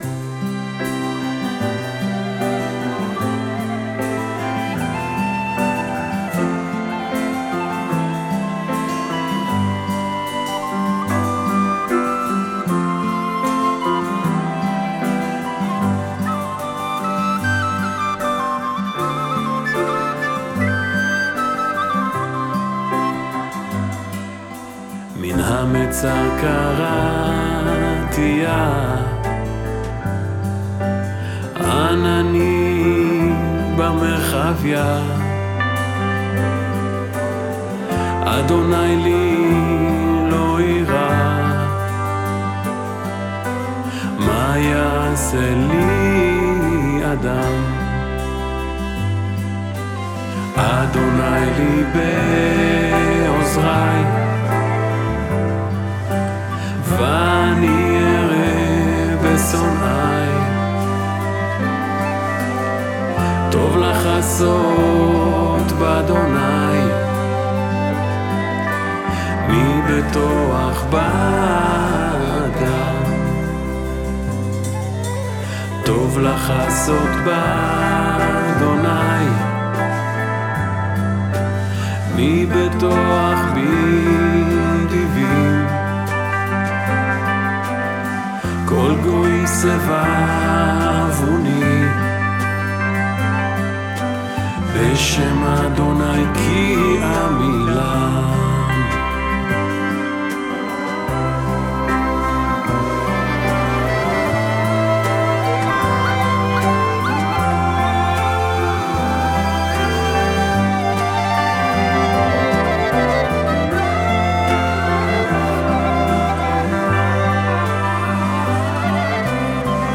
עם האנסמבל הירושלמי שהקים.